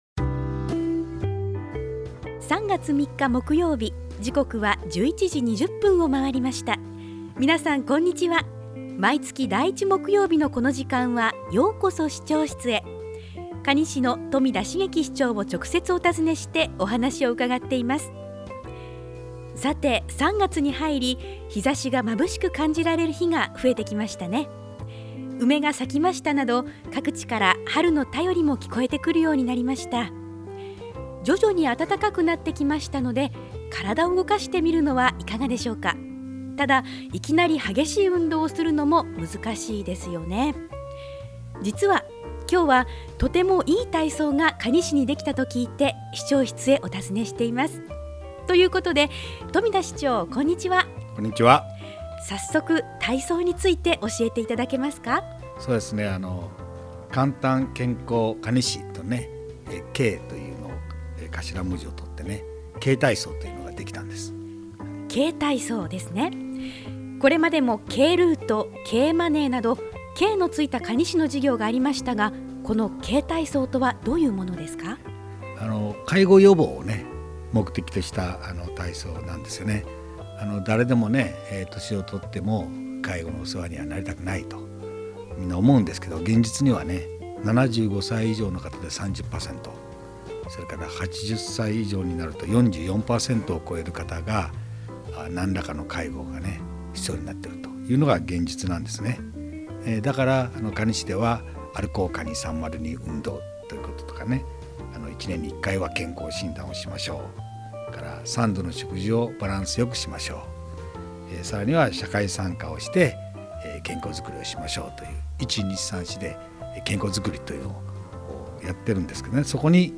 ようこそ市長室へ 2016-03-03 | ようこそ市長室へ 「ようこそ市長室へ」 可児市長室へ直接伺って、まちづくりの課題、魅力ある地域、市政情報などを 中心に、新鮮な情報を 冨田市長の生の声を、皆様にお届けする番組です。 ◎ 放送時間 毎月第１木曜日 １１：２０～ 可児市長 ： 冨田 成輝 ▼ 平成２８年 ３月 ３日 放送分 【今回のテーマ】 「みんなでＫ体操 みんなで介護予防」 Podcast: Download « ようこそ市長室へ ようこそ市長室へ »